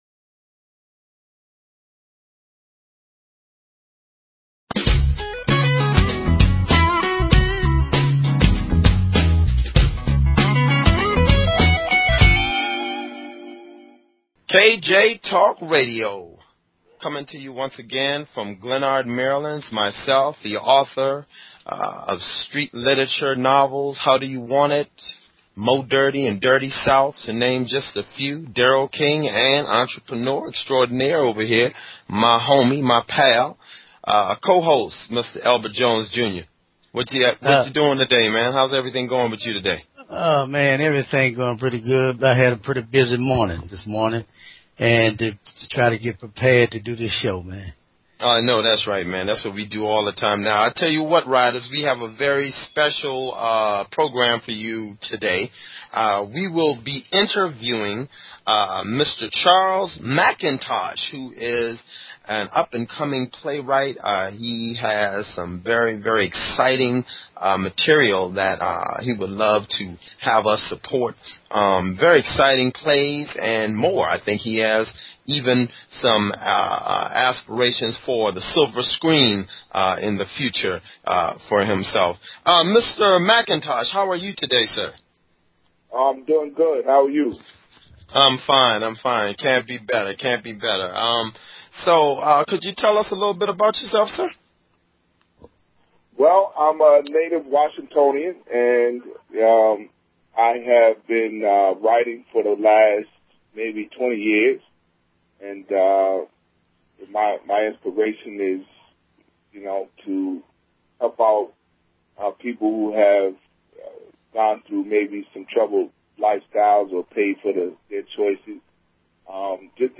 Talk Show Episode, Audio Podcast, KJ_Talk_Radio and Courtesy of BBS Radio on , show guests , about , categorized as
KJ Talk radio is an un opinionated, and open forum which provides a platform for a wide variety of guests, and callers alike.